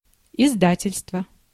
Ääntäminen
Ääntäminen Tuntematon aksentti: IPA: /ɪzˈdatʲɪlʲstvə/ Haettu sana löytyi näillä lähdekielillä: venäjä Käännös Ääninäyte Substantiivit 1. publishing house 2. publisher US Translitterointi: izdatelstvo.